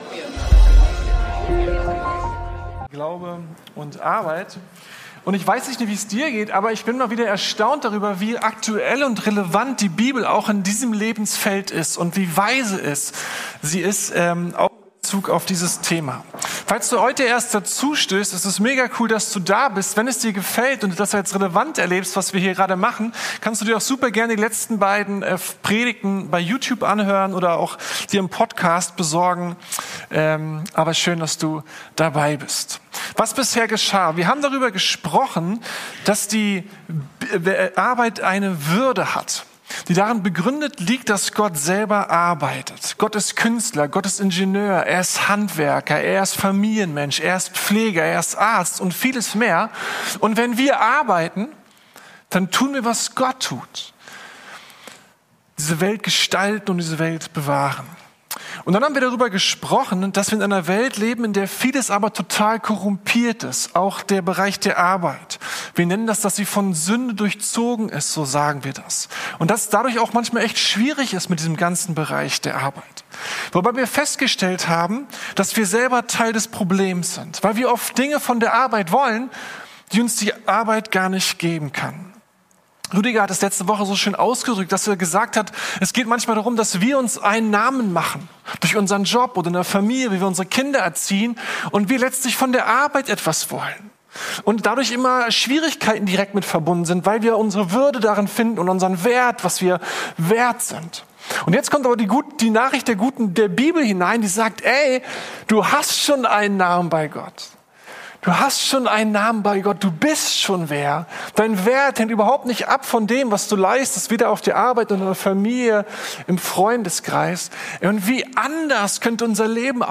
Glaube und Arbeit: Mit Gott auf der Arbeit ~ Predigten der LUKAS GEMEINDE Podcast